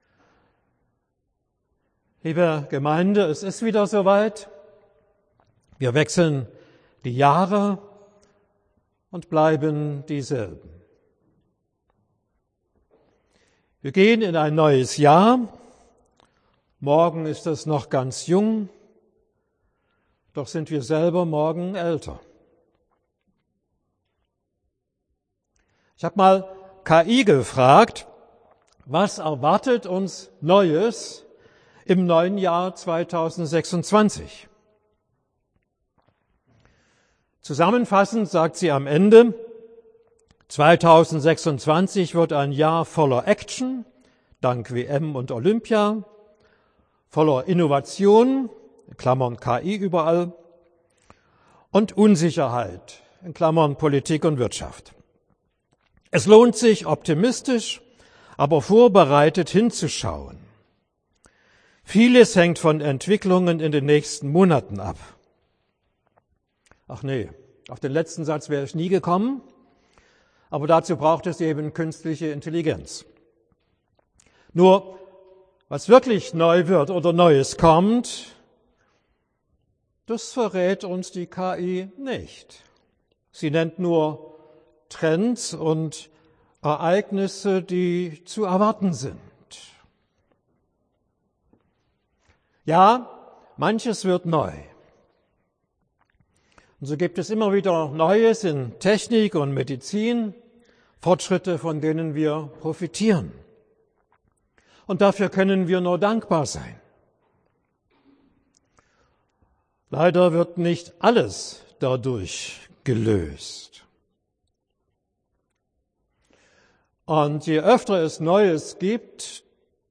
Predigt über Offenbarung 21,5 und 1. Thessalonicher 5,21: Jahreslosungen zum Jahreswechsel